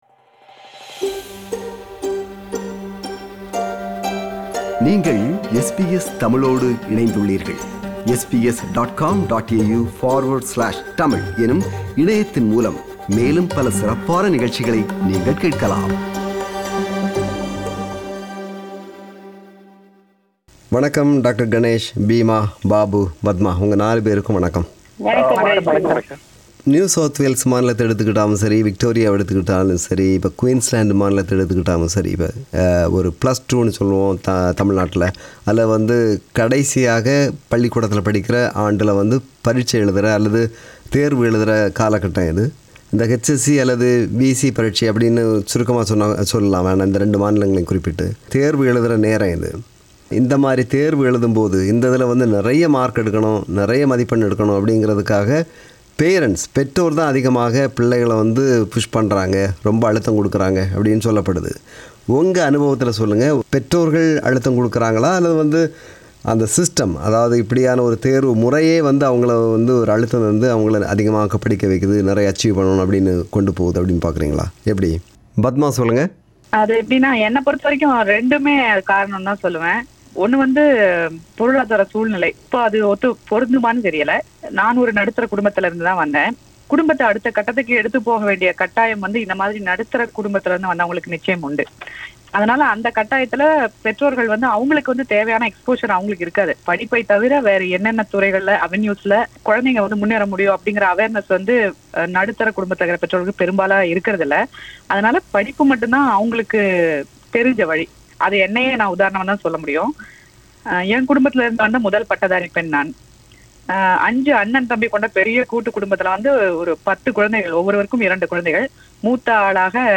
Participants of the panel discussion